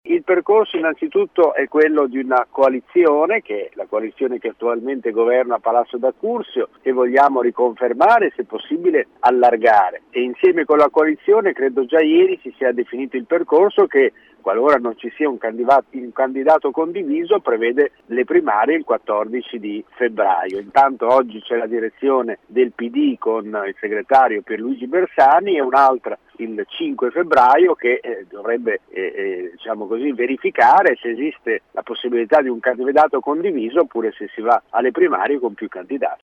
Ai nostri microfoni, l’ex sindaco di Bologna e senatore Pd, Valter Vitali, ha sottolineato come l’idea del partito sia quella di confermare l’alleanza che governava il comune e, eventualmente, di tentarne un allargamento.
Ascolta il senatore Valter Vitali